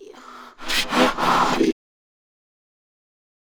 Techno / Voice / VOICEFX252_TEKNO_140_X_SC2(R).wav